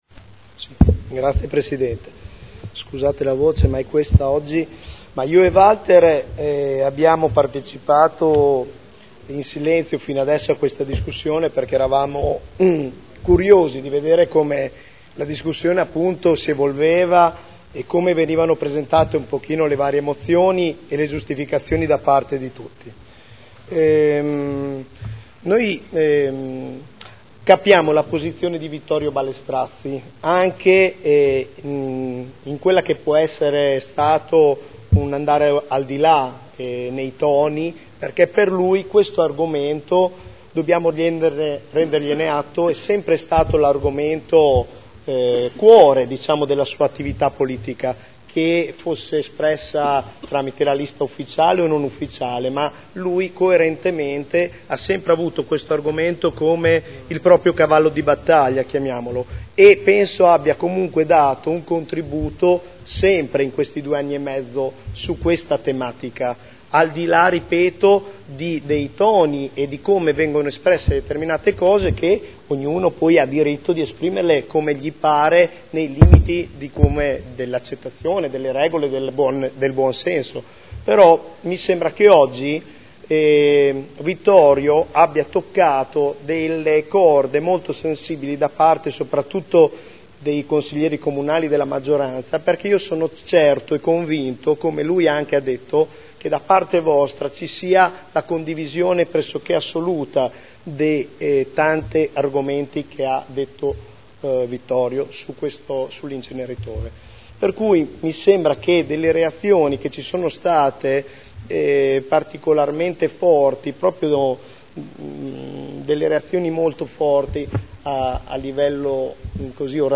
Nicola Rossi — Sito Audio Consiglio Comunale